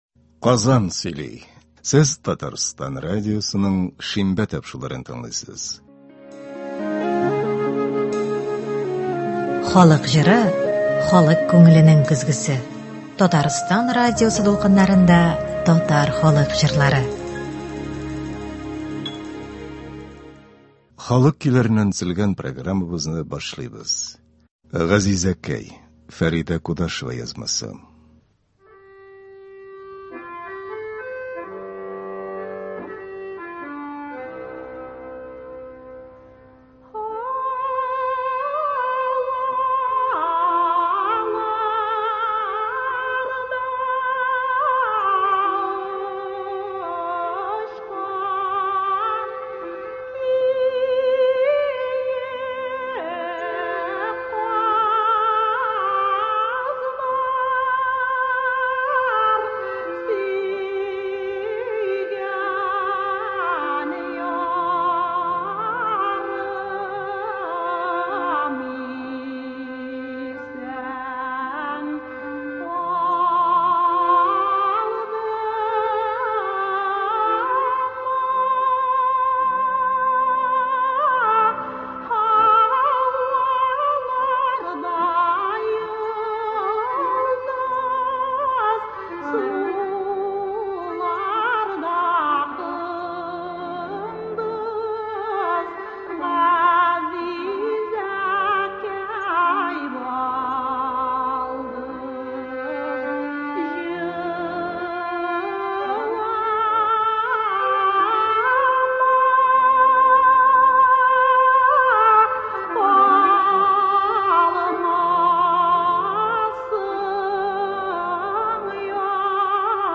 Татар халык көйләре (24.09.22)